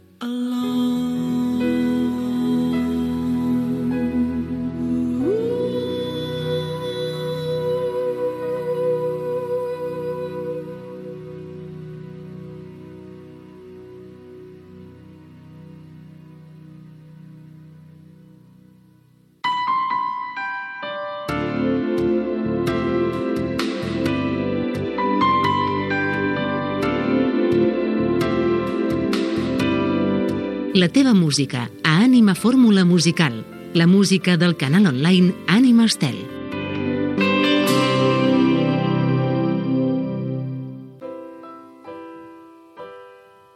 Música i identificació Gènere radiofònic Musical